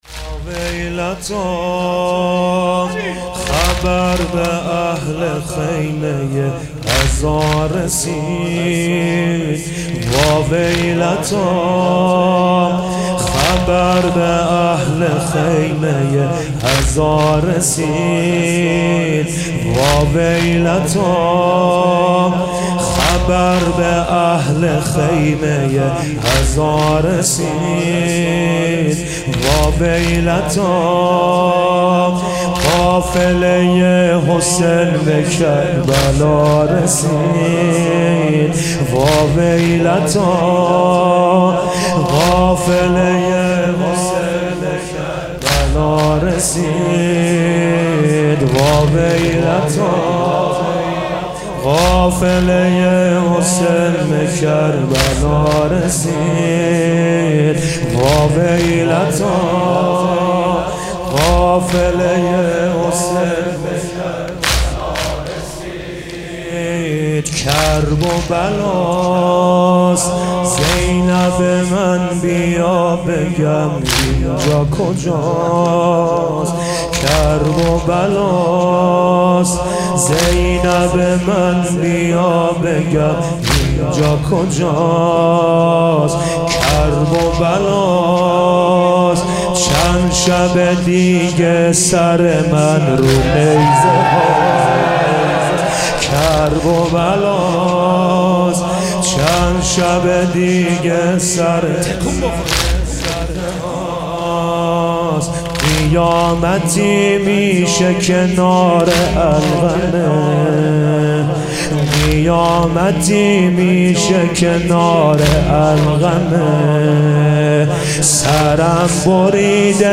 مداحی جدید
شب دوم محرم۹۸ مجمع حیدریون زنجان